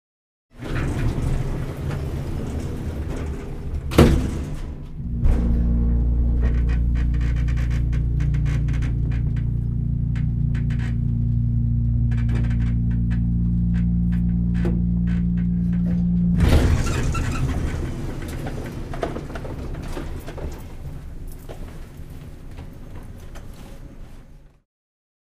Звуки лифта
Звук грузового лифта: скрип дверей и гул движения